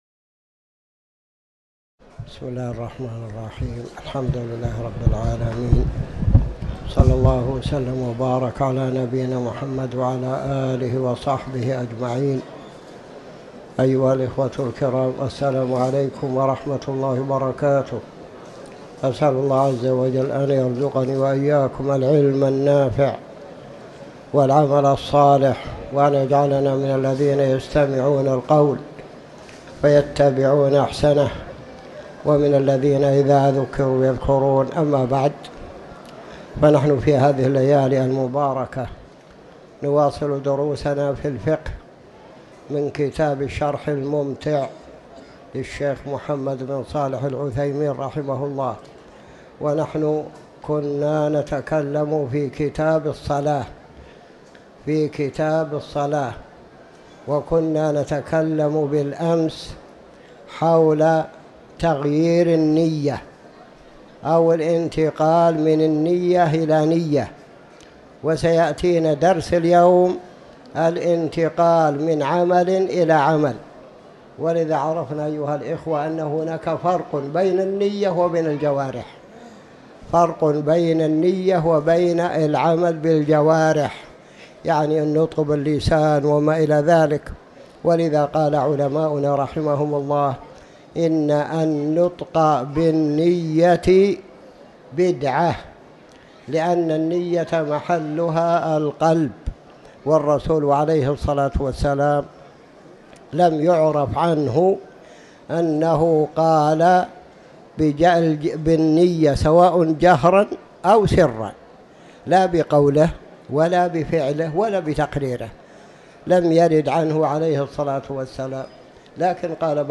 تاريخ النشر ٢١ شوال ١٤٤٠ هـ المكان: المسجد الحرام الشيخ